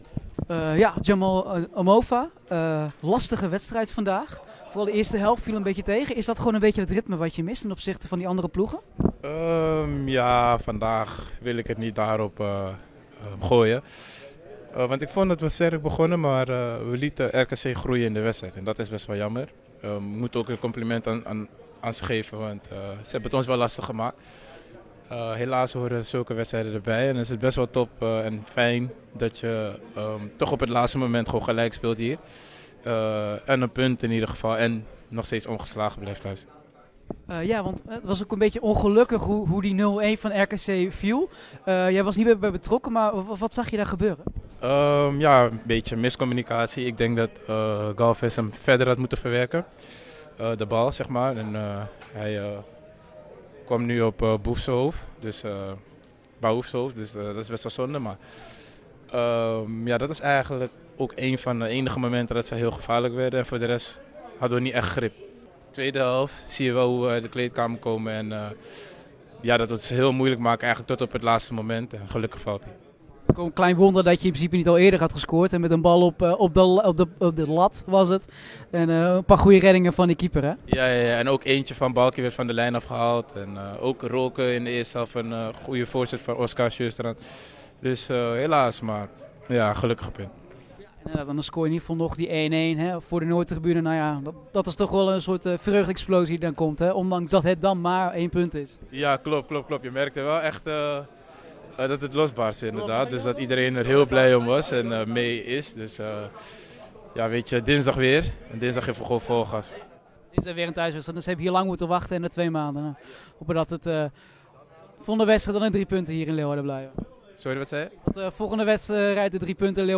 Wij spreken met hoofdrolspelers